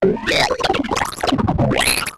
AFX_DROIDTALK_2_DFMG.WAV
Droid Talk 2